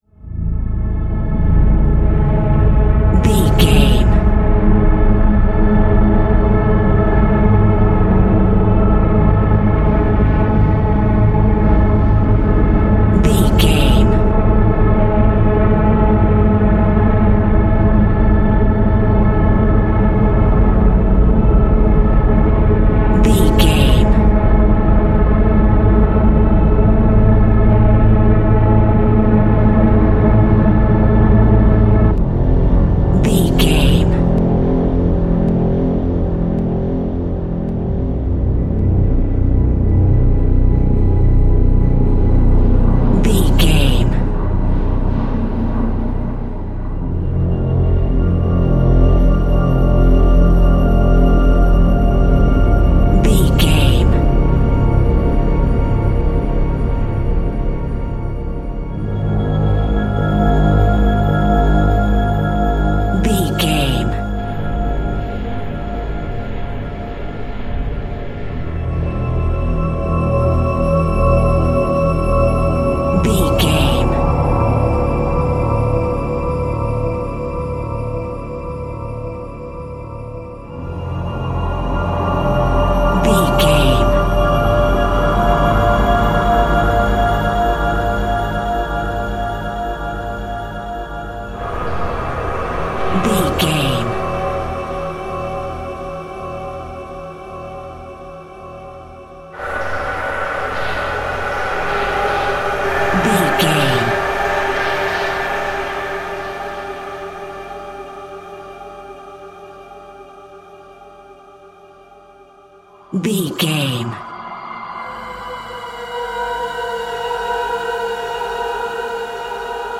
120 Seconds Horror Build Up.
In-crescendo
Atonal
Slow
scary
tension
ominous
dark
suspense
haunting
eerie
strings
synthesiser
keyboards
ambience
pads